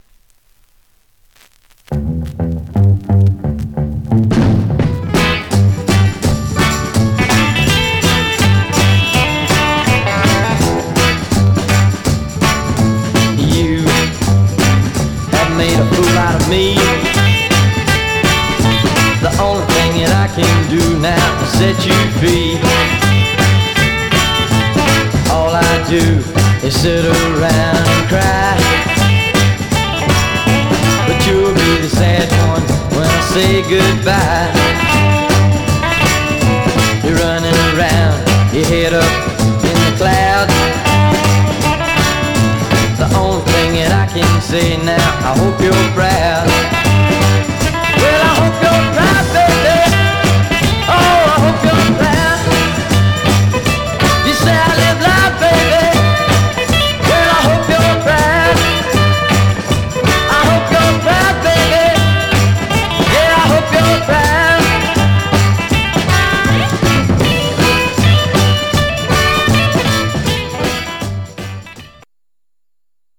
Some surface noise/wear
Mono
Garage, 60's Punk